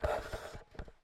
Здесь вы найдете как знакомые «хрустящие» эффекты корзины, так и более современные варианты.